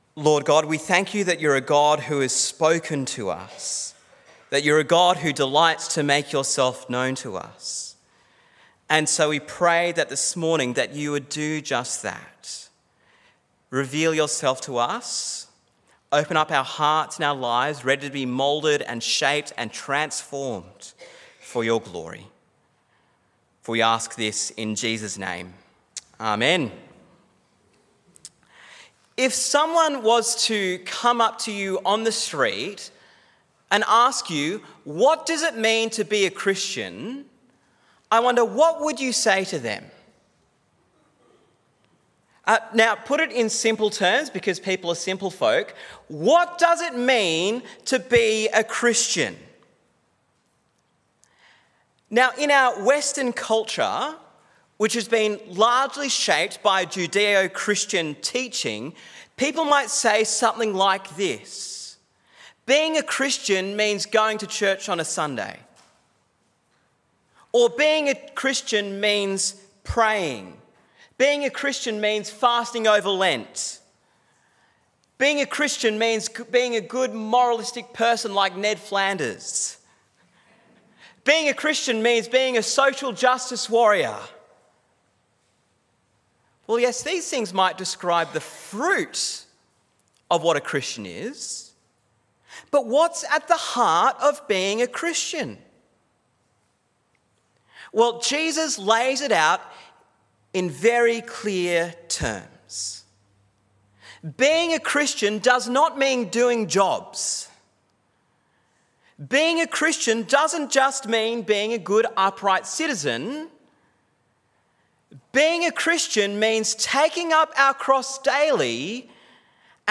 Sermon on Mark 8:27-38